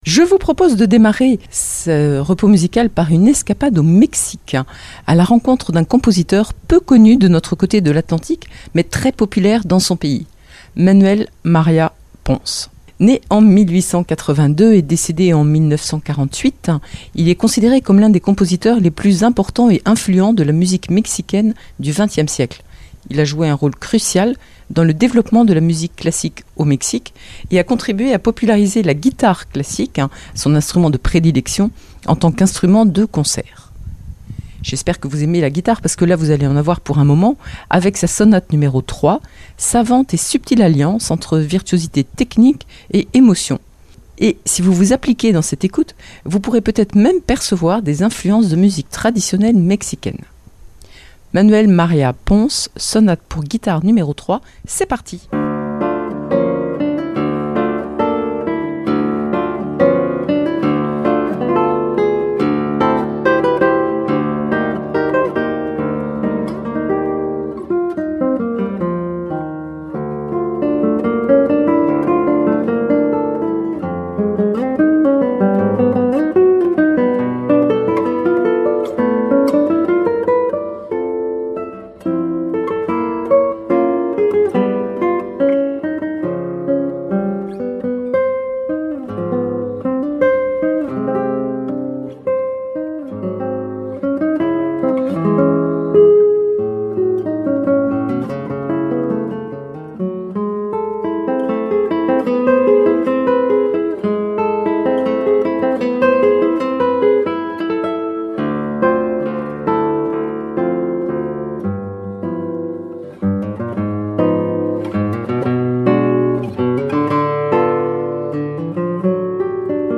Calme et douceur